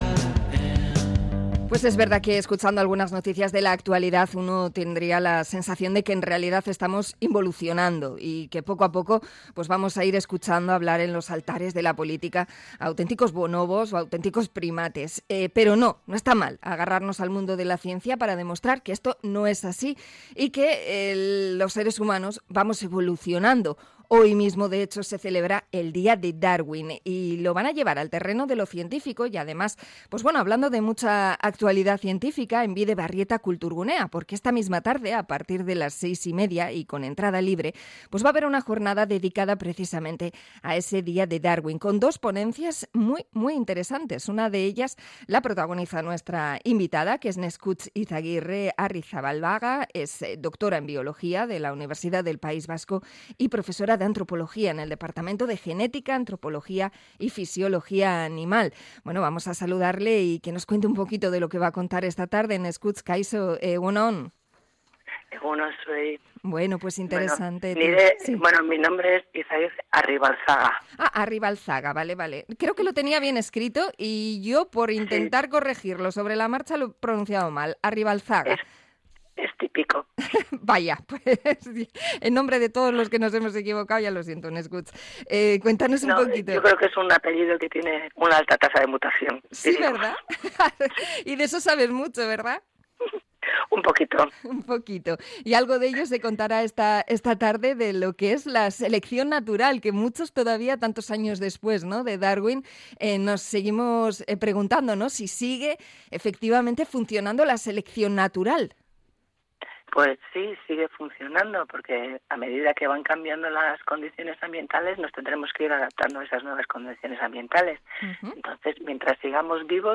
Entrevista a bióloga sobre genética en el Día de Darwin